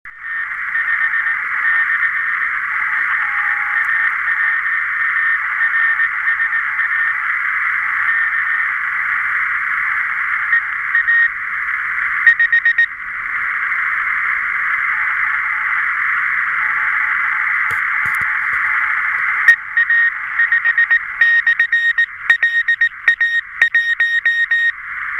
Выходил в леса-поля, поддержал участием, работая QRP/p мини- тест клуба "5-й океан".